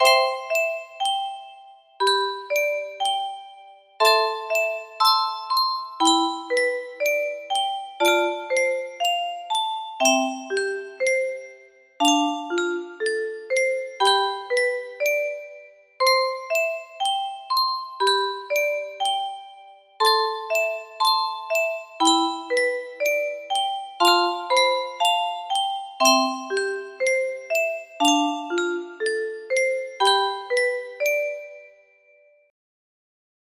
music box melody
Grand Illusions 30 (F scale)